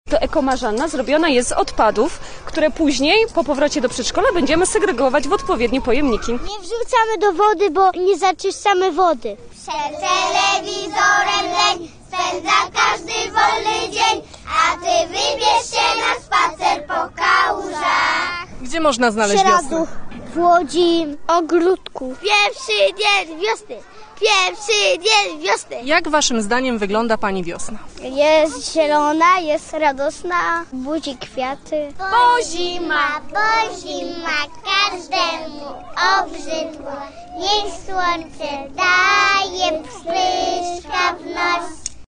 Sieradz rozbrzmiewa dziś śpiewem przedszkolaków, które wybrały się na pierwsze wiosenne spacery.